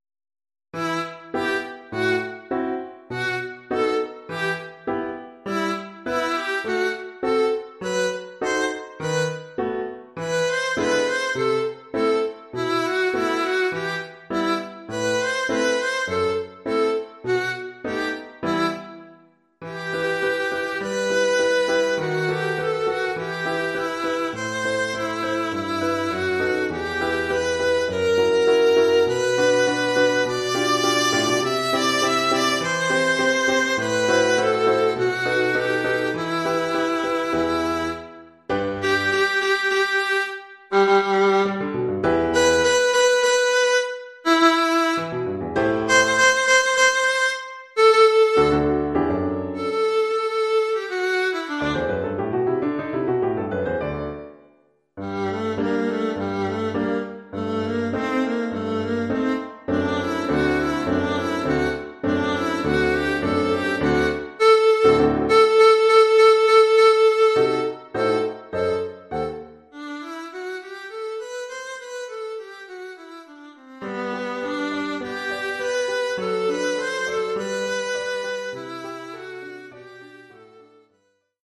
1 titre, alto et piano : conducteur et partie d’alto
Oeuvre pour alto avec accompagnement de piano.
accompagnement de piano".